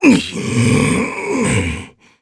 Ricardo_Vox_Sad_jp.wav